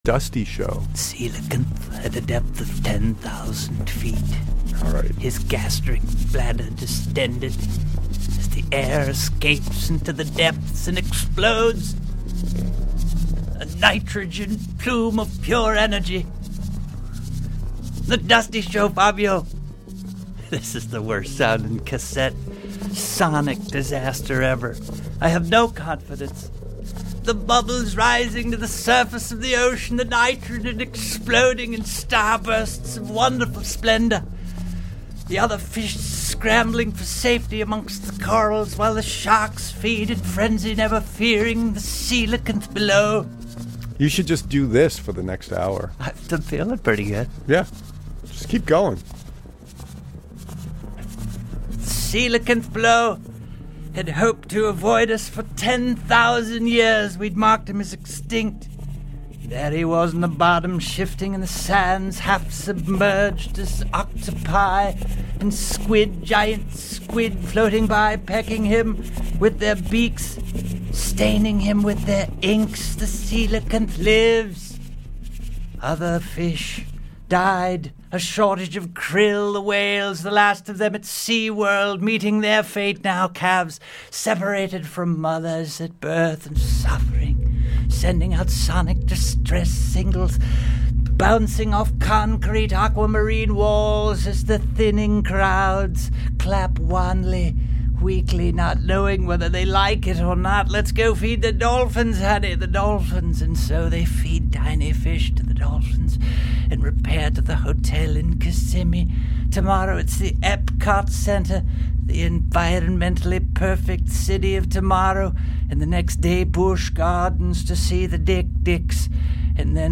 Dubbed to cassette.
Acoustic Guitar/Vocals
[Music behind DJ]